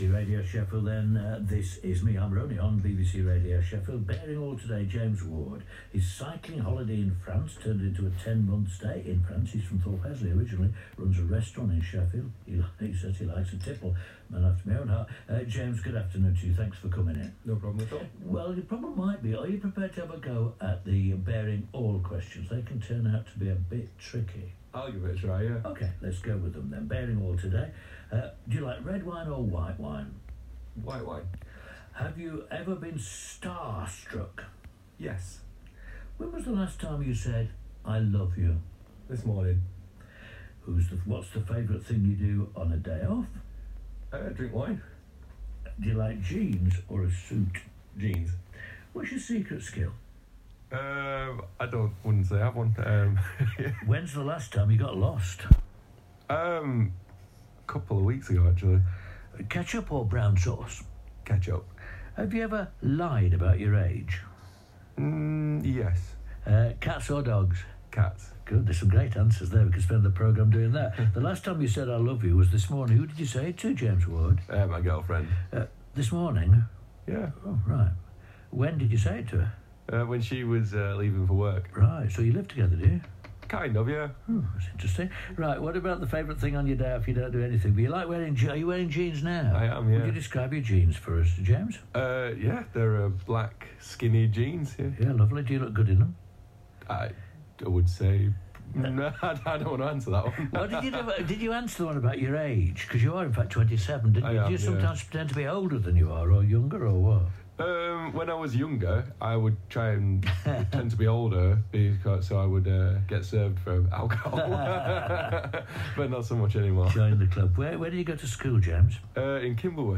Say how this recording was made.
radio-sheffield.m4a